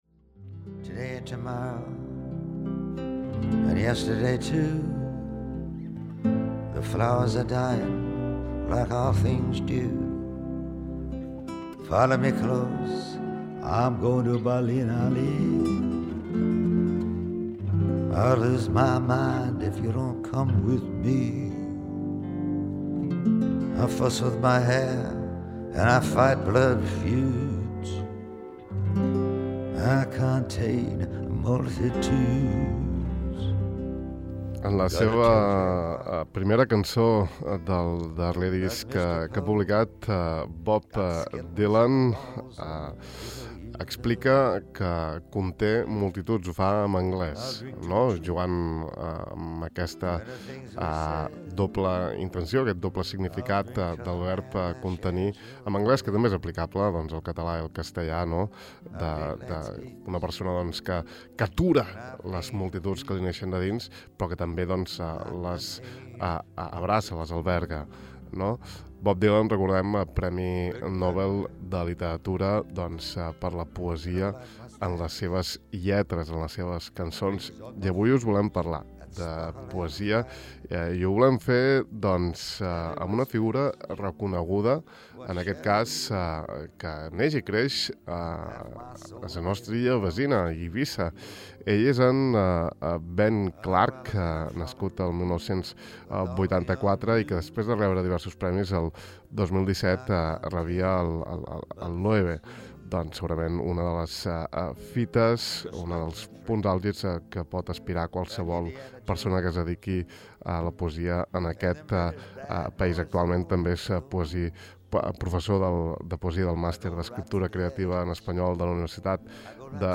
Aquí l’entrevista que li hem fet al De far a far: